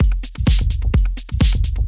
Electrohouse Loop 128 BPM (39).wav